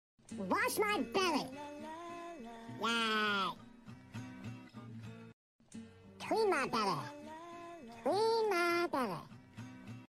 Meme Sound Effects